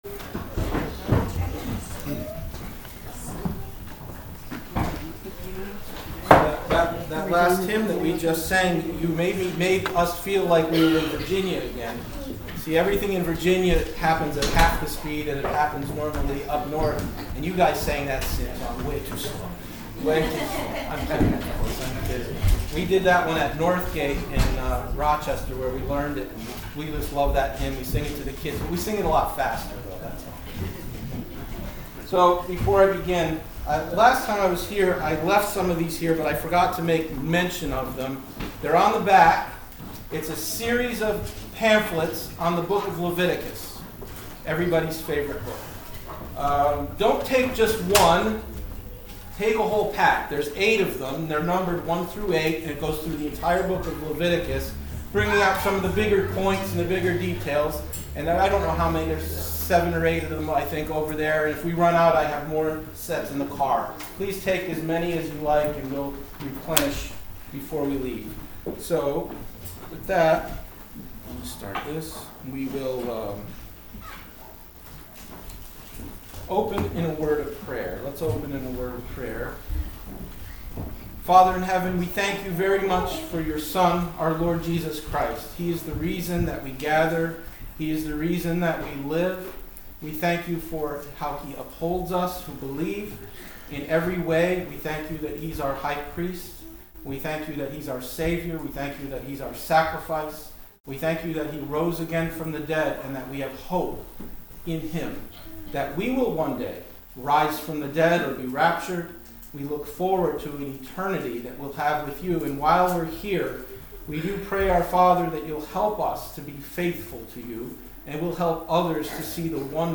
Both King and Priest Passage: Gen 14, Psalms 110 Service Type: Sunday Afternoon « 05.03.25